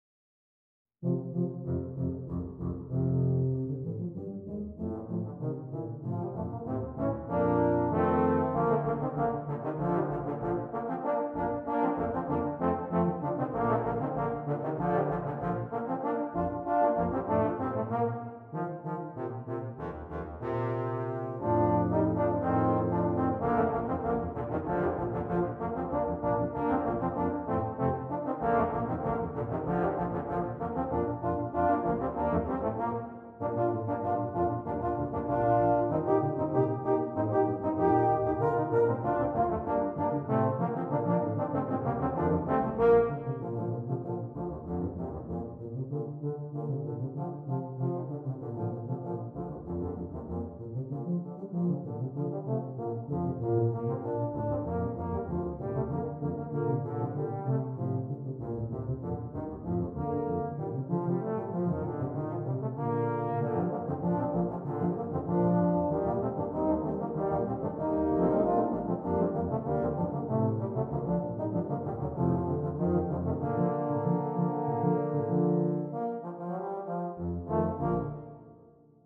2 Euphoniums, 2 Tubas
Traditional